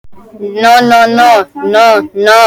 Play, download and share FUNNY NO original sound button!!!!